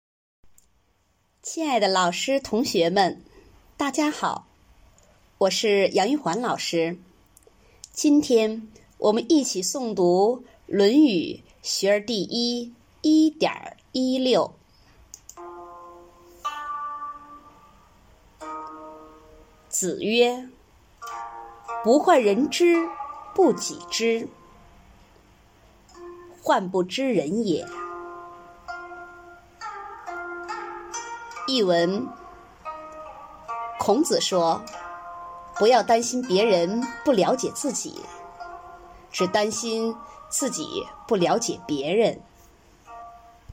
每日一诵0309.mp3